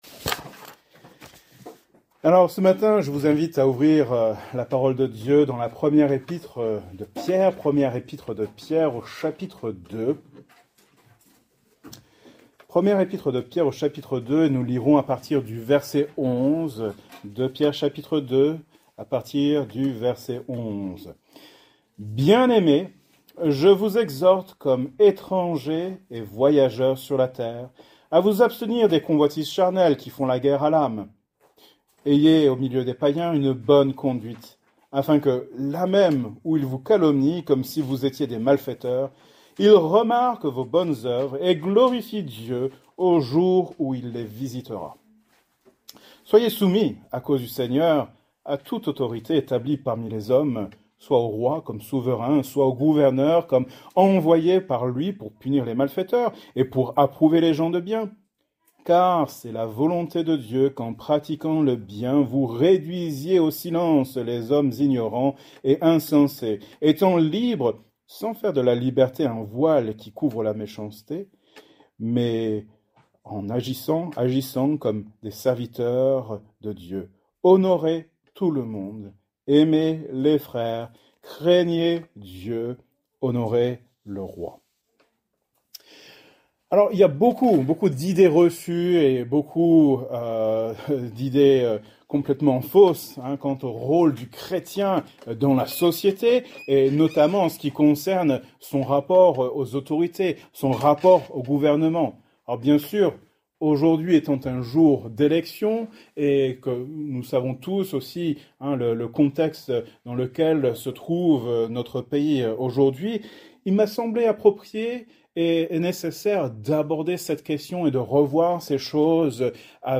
Genre: Prédication